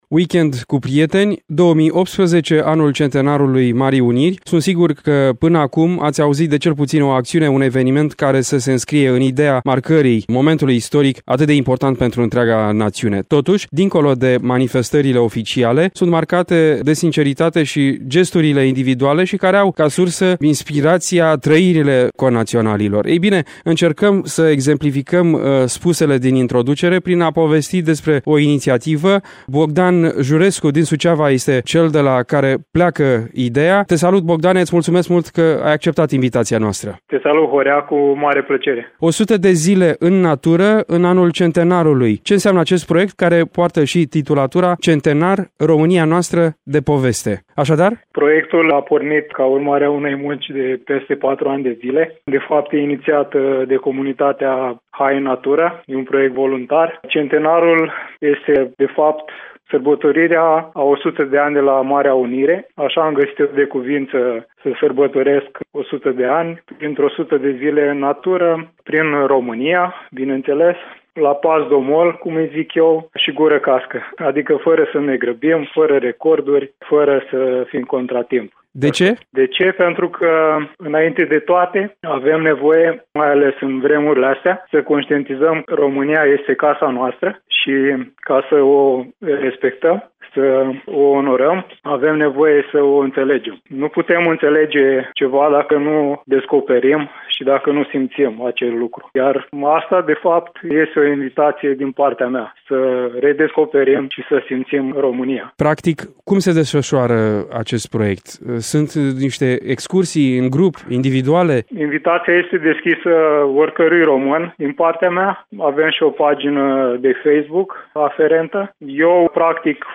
100 de zile în natură, în anul centenarului. Ce înseamnă acest proiect, răspunsuri într-un interviu